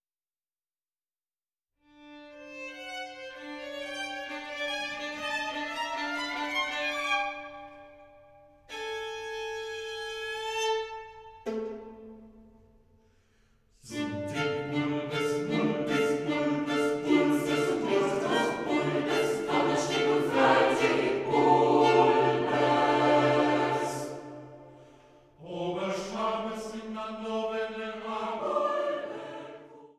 Sopran
Violine
Kontrabass
Klavier